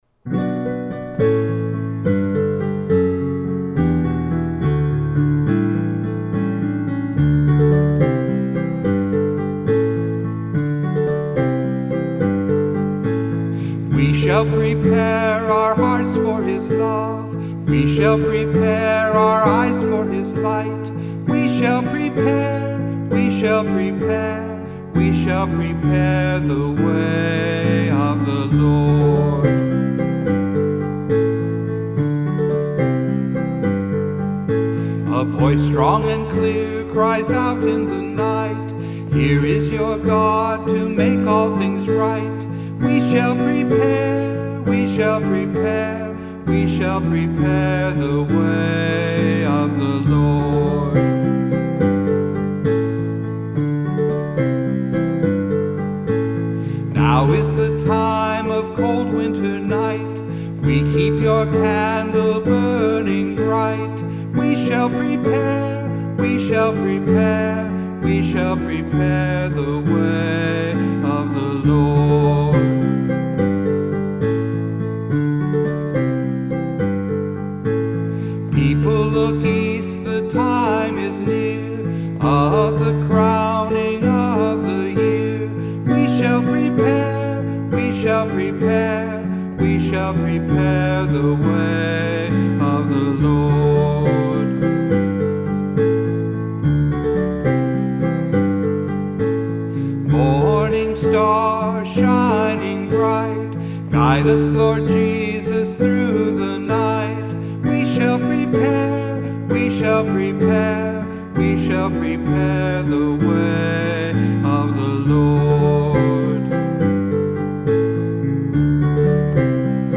A Song for Advent